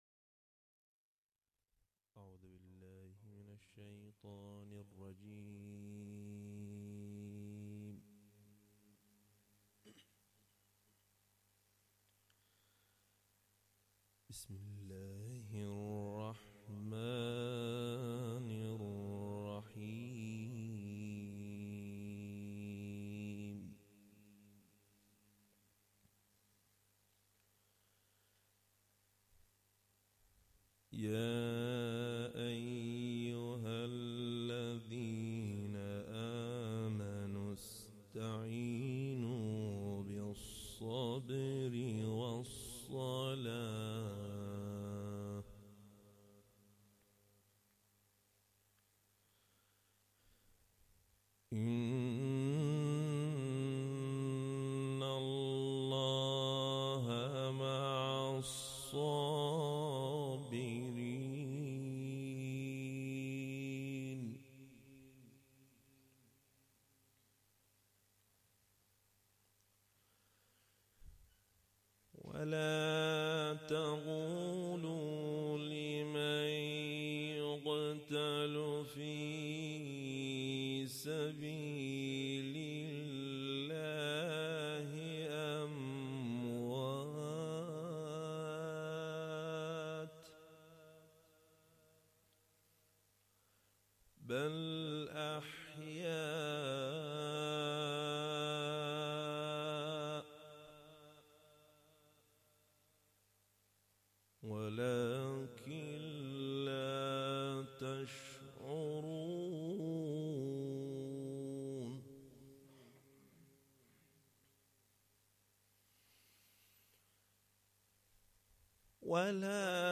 قرآن
محفل ذکر شهدا و عزاداری وفات ام البنین ۹۹